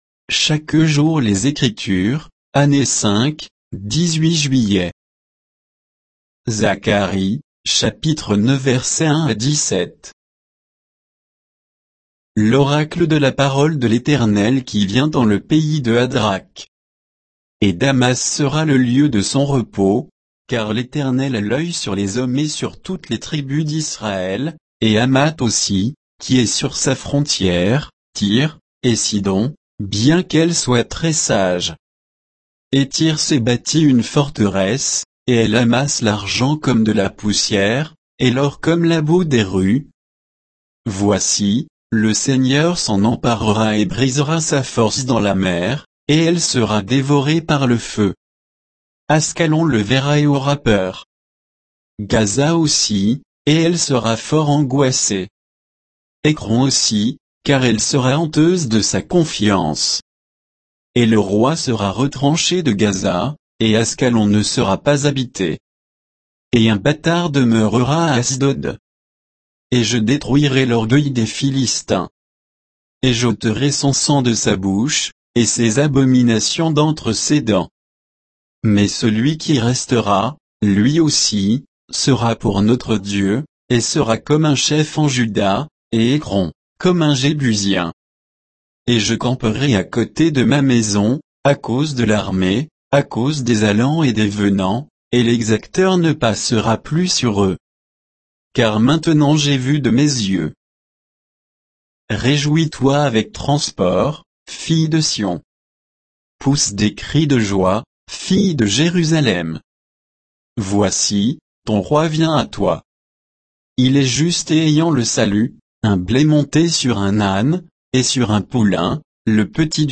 Méditation quoditienne de Chaque jour les Écritures sur Zacharie 9